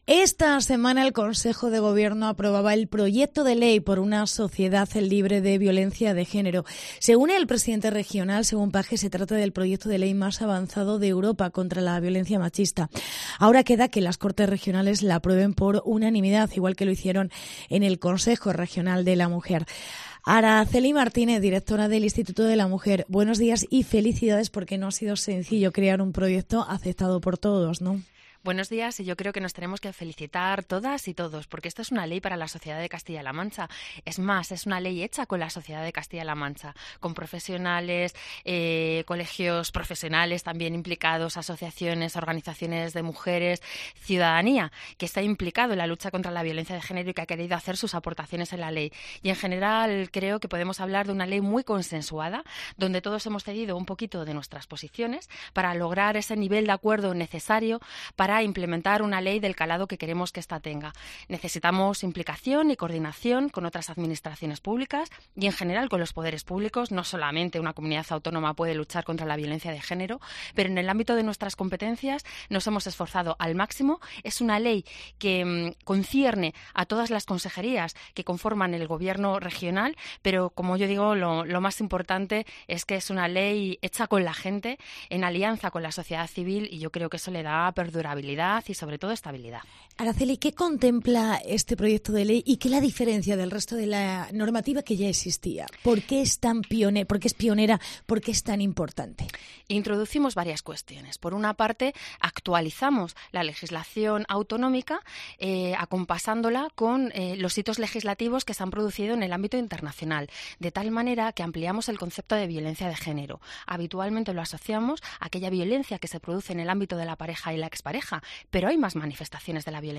Entrevista con Araceli Martínez. Directora del Inst. de la Mujer